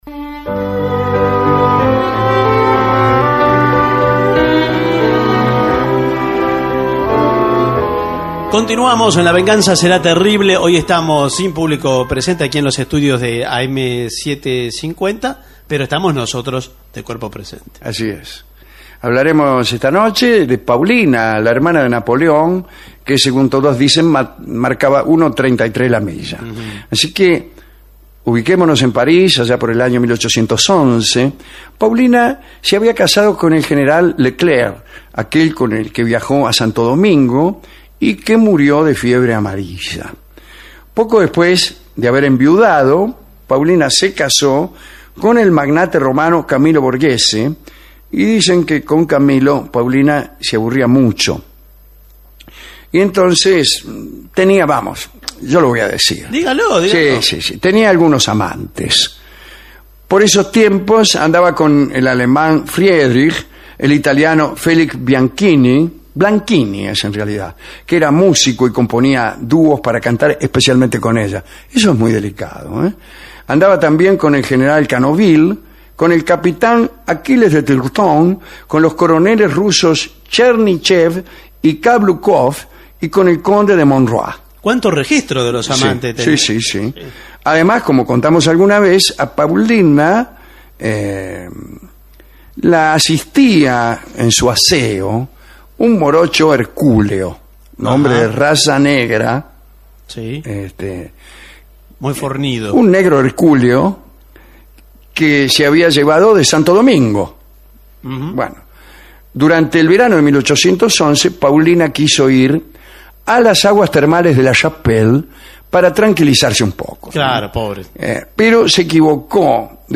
Estudios de Radio El Mundo (AM 1070 kHz), 1987